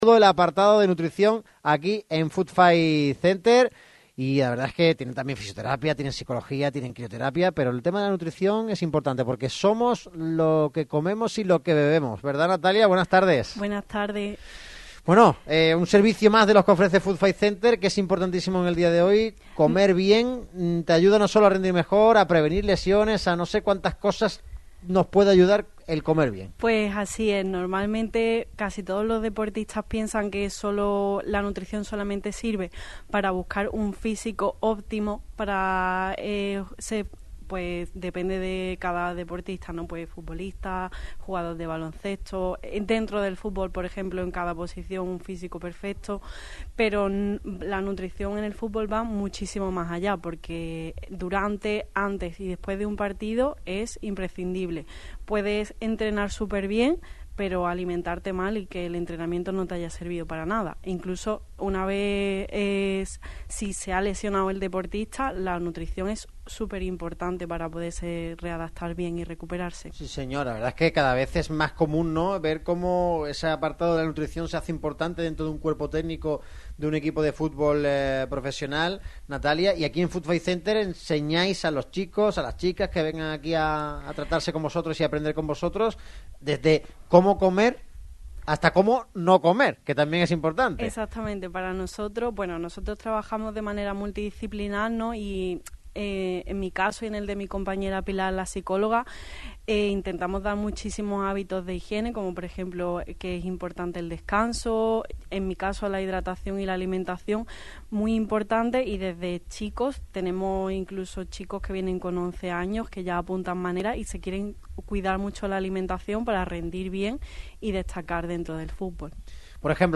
Análisis, información y sobre todo mucho debate en el programa de este martes; rodeados de las instalaciones de FootFay Center y su última tecnología, el centro de alto rendimiento líder en la capital costasoleña.